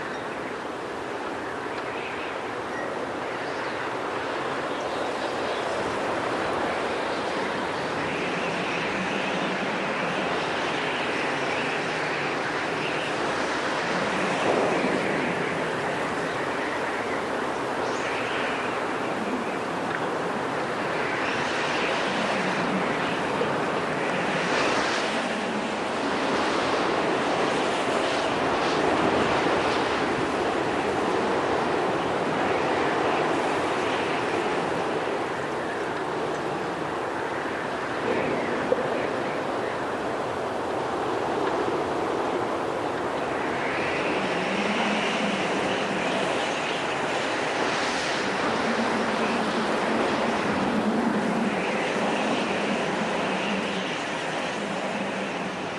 Звуки дуновения
Ветер с песком по щекам